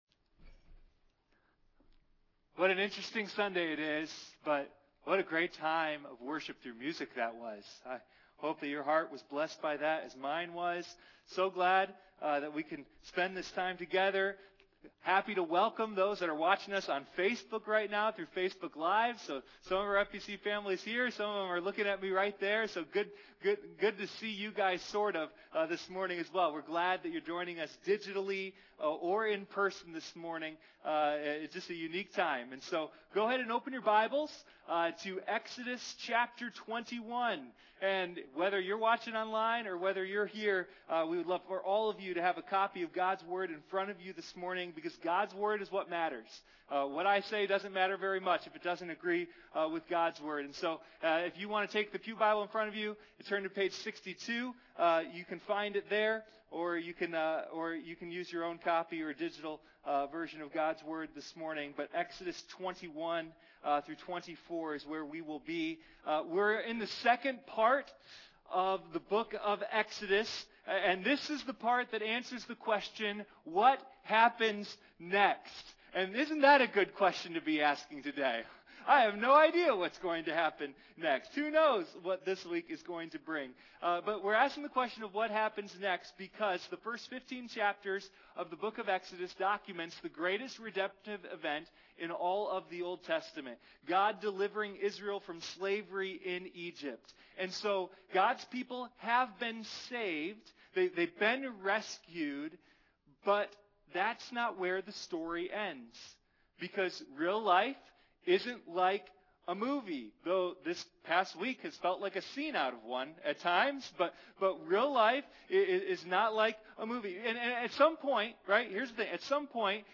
Sunday Morning Communion: A Study in Exodus